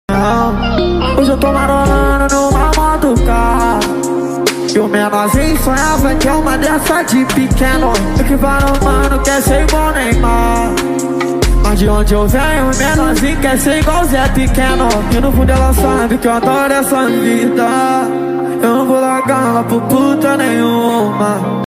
2024-06-12 22:11:53 Gênero: Trap Views